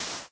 sand1.ogg